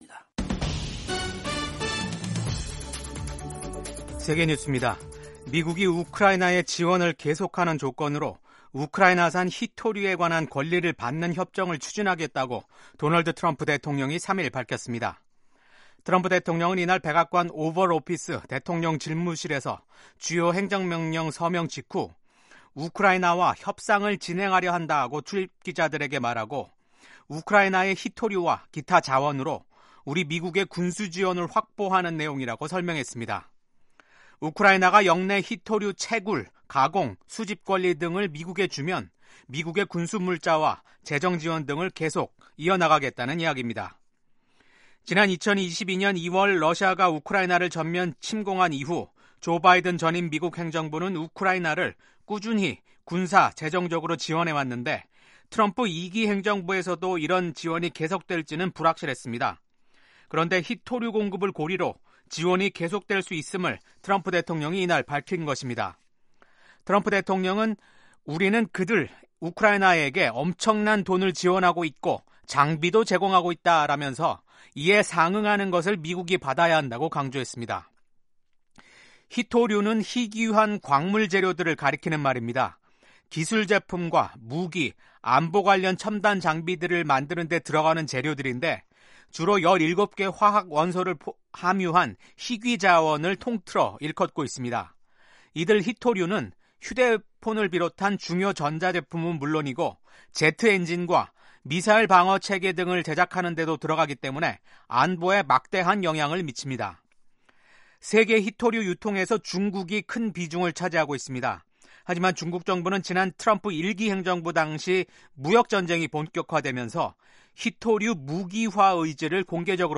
생방송 여기는 워싱턴입니다 2025/2/5 아침